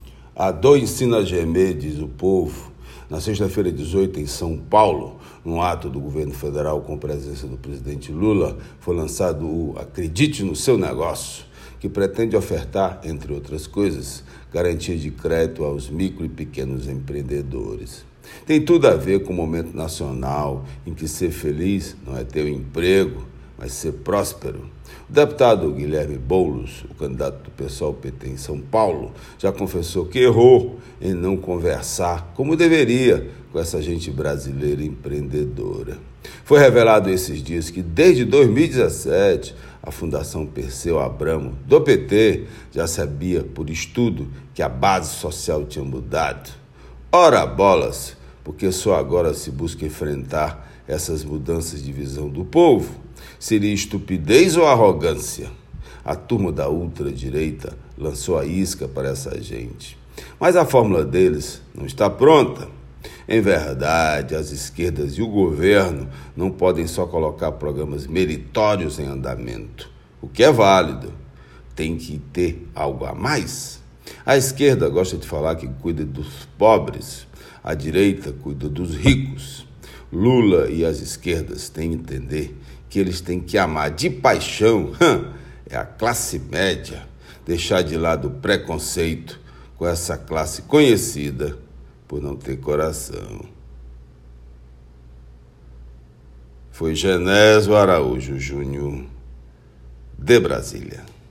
Comentário
direto de Brasília.